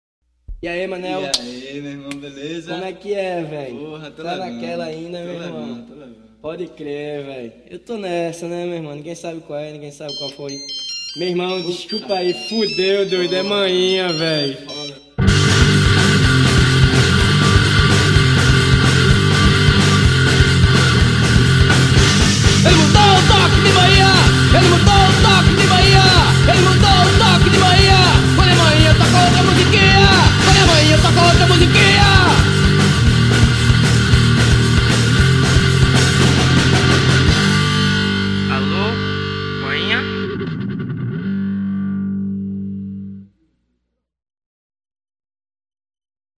Som direto, sem excesso, com peso e intenção.